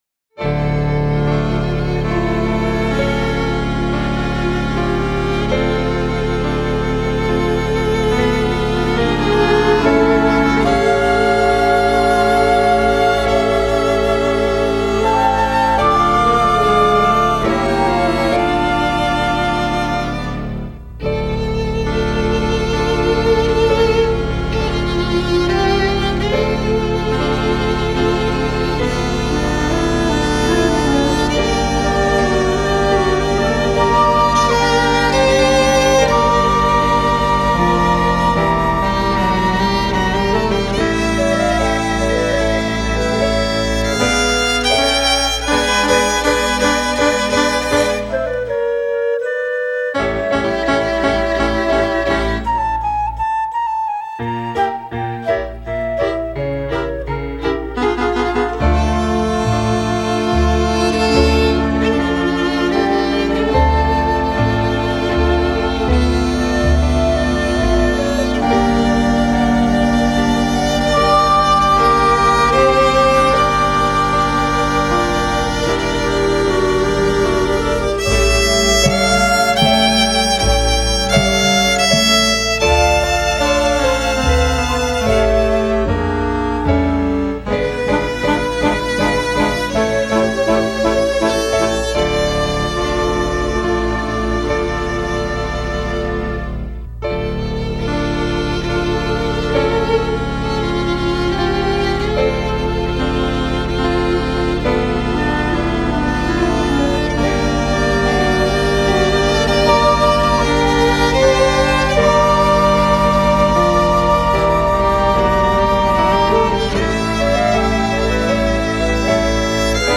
814   10:08:00   Faixa:     Valsa